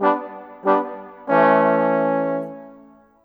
Rock-Pop 06 Brass 04.wav